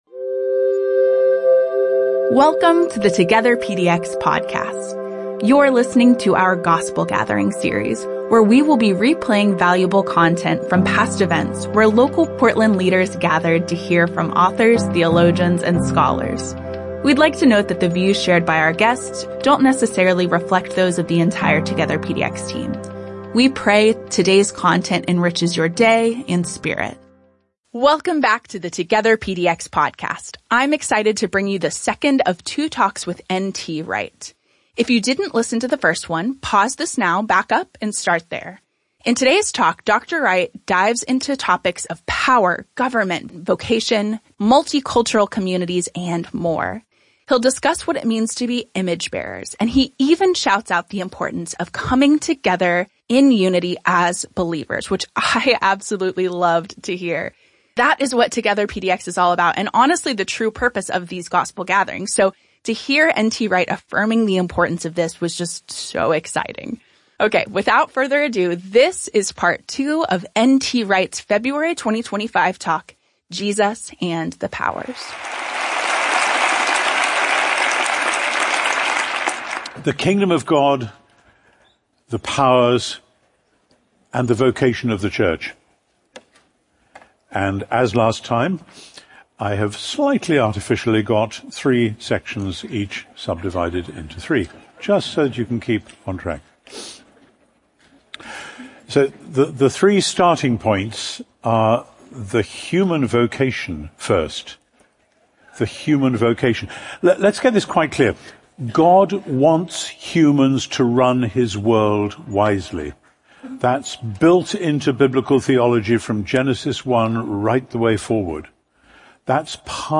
N.T. Wright joined more than 2,000 church leaders from the Portland, OR metro area in February 2025. This is part 2 of his talk, "Jesus and the Powers."